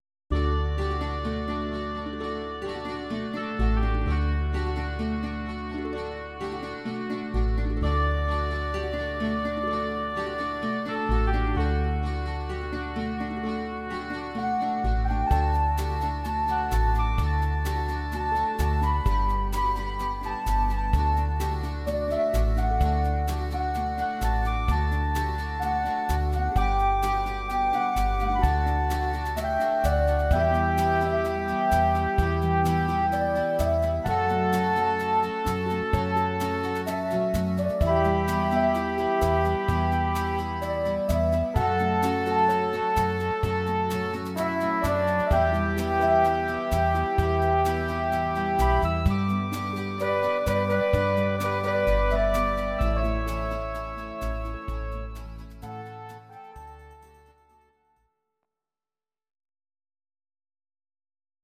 Audio Recordings based on Midi-files
Oldies, Duets, 1960s